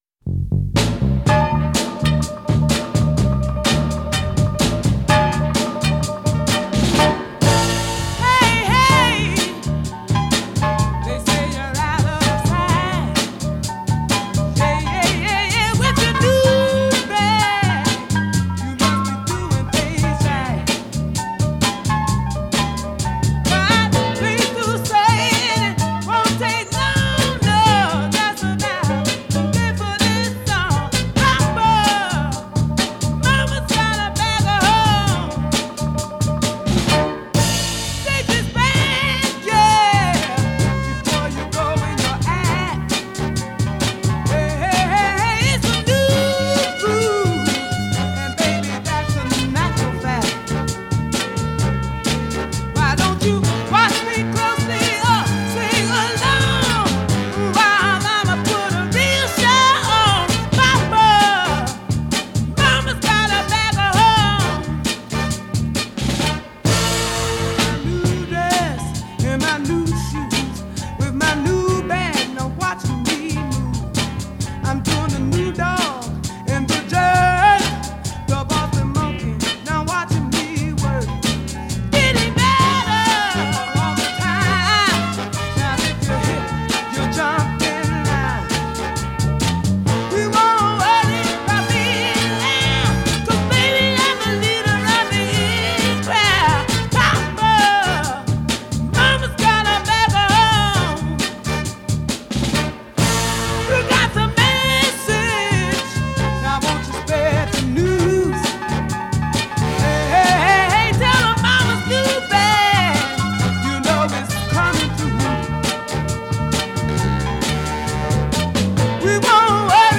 file under funky soul